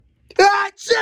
achoo_dwYVKlJ.mp3